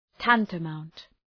Προφορά
{‘tæntə,maʋnt}
tantamount.mp3